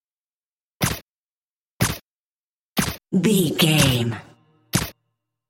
Pistol Firing with Silencer 04 | VGAME
Filled with 5 sounds(44/16 wav.) of Pistol Firing(Five single shots) with silencer.
Sound Effects
Adobe Audition, Zoom h4
muted